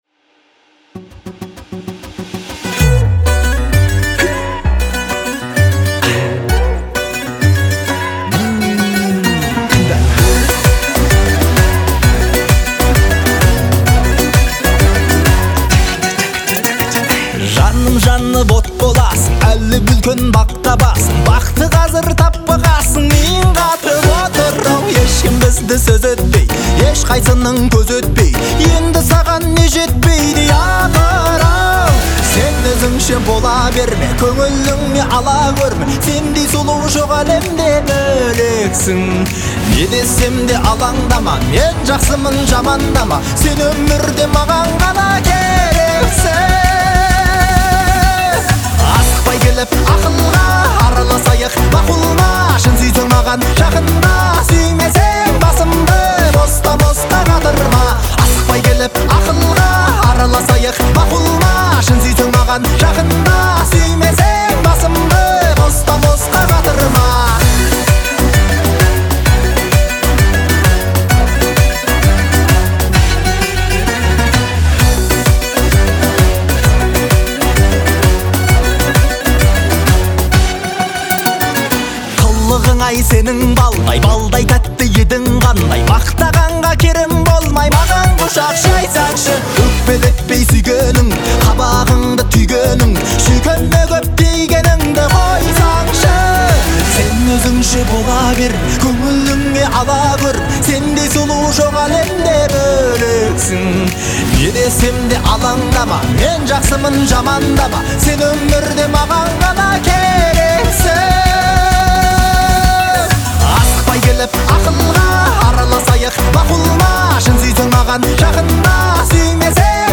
Эстрадалық әндер